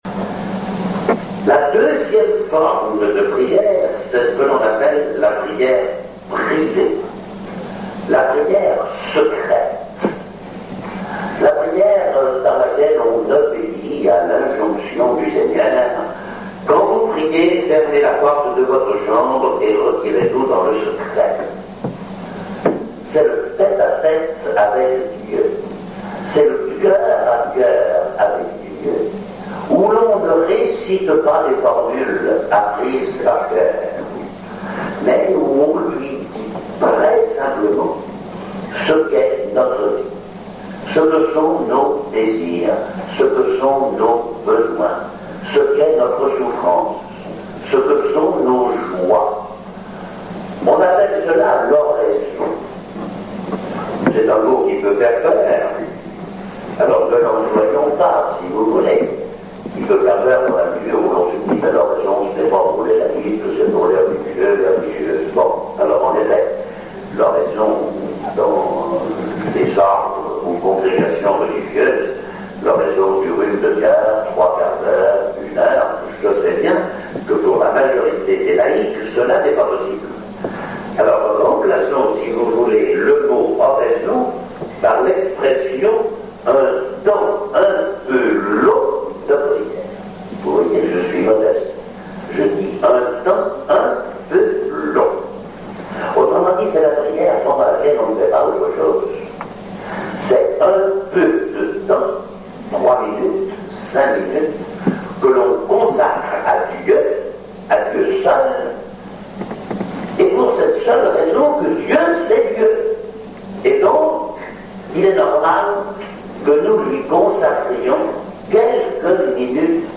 Extraits d’une conférence